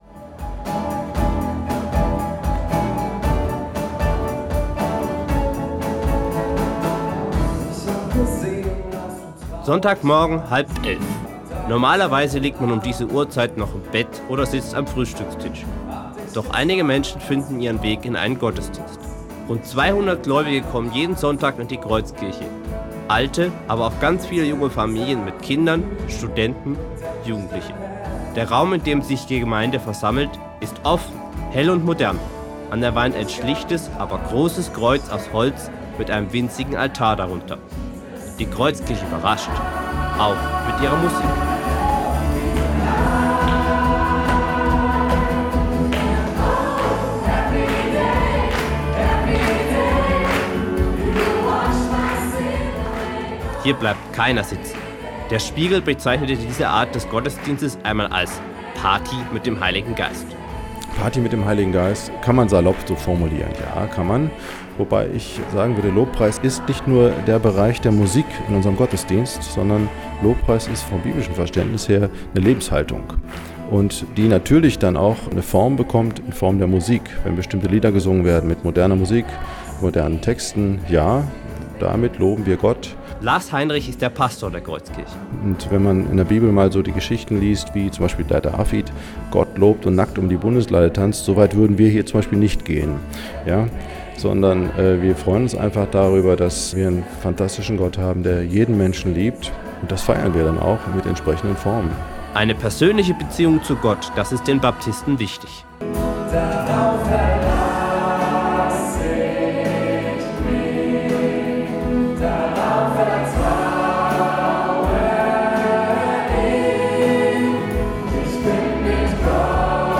Reportage-Kreuzkirche-Tuebingen.ogg